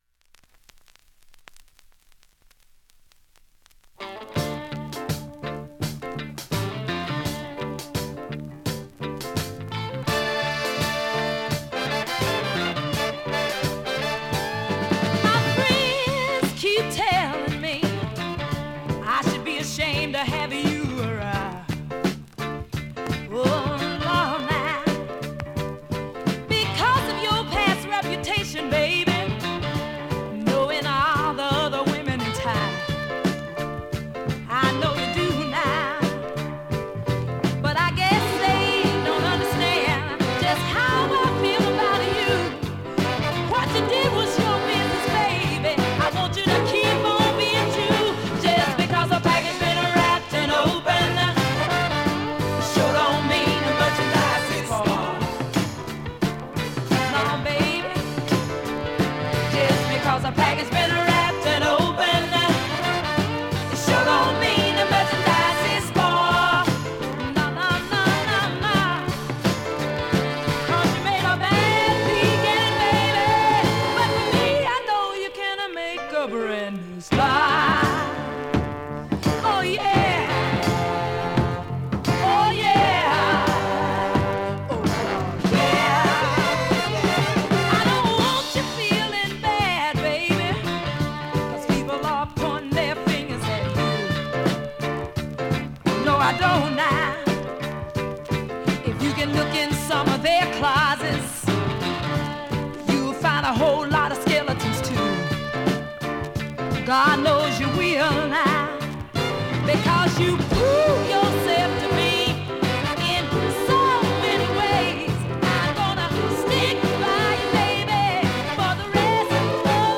ミッドテンポのナイスシスターファンク